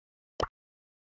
mouseenter_sound_04.mp3